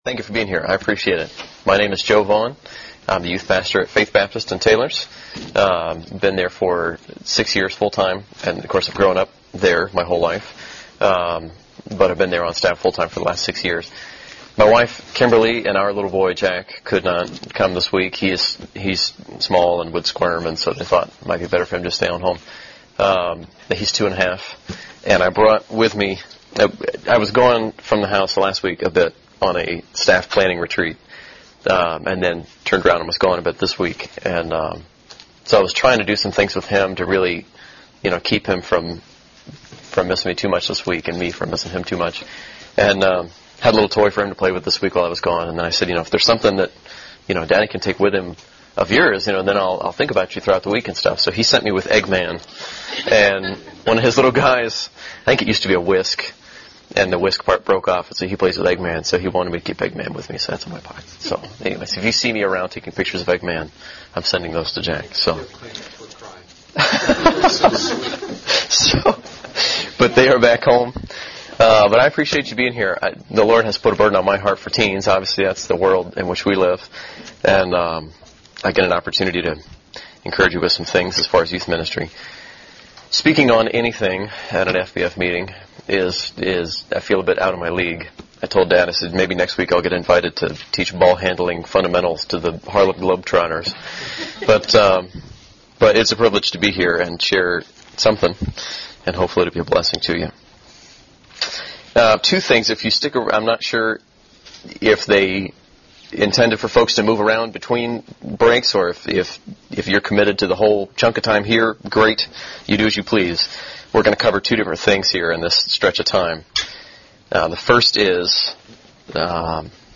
Workshop